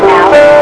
Trolley Square E.V.P.
These two EVP below were recorded in the TS Live theatre, they hold many stand up comedy acts as well as comedy hypnotists.
I was asking "Walt" (the man whom they think haunts the mall) to come out and play and there is this voice that answers back and says what sounds like "HELP"
help.wav